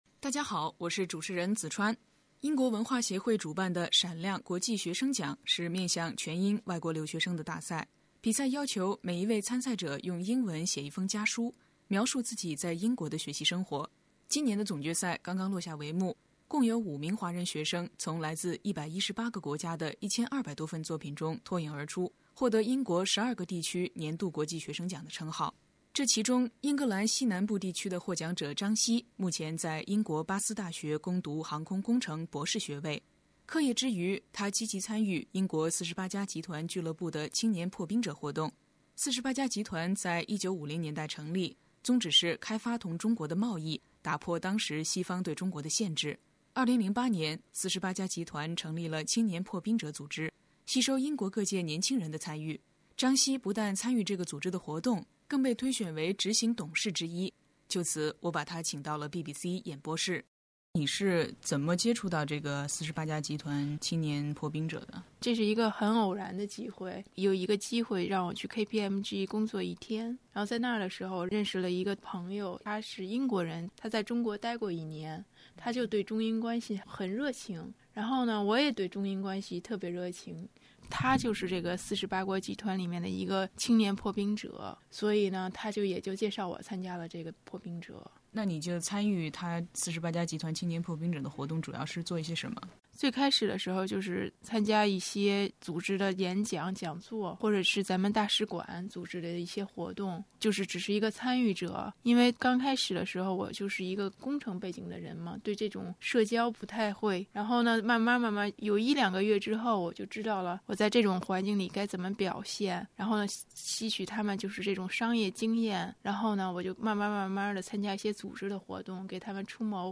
在访谈的下半部分